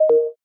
cancel.aiff